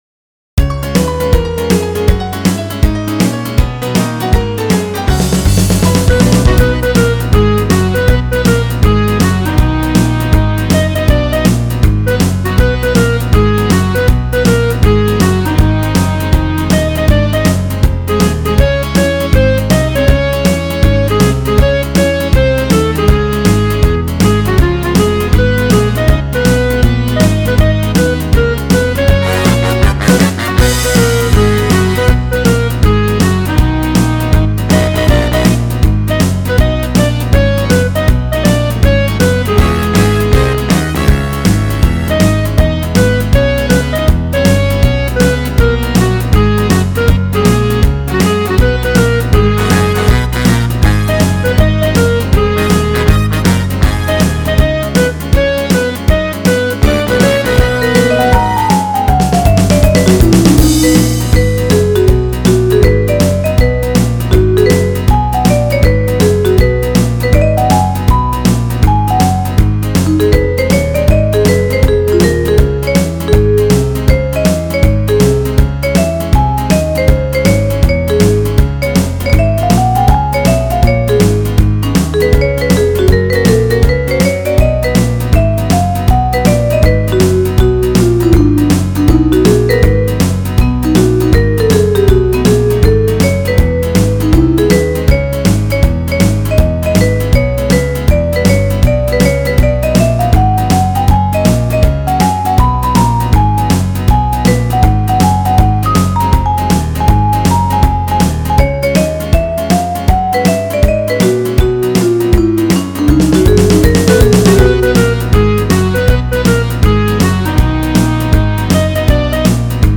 A novelty song with a jazz feel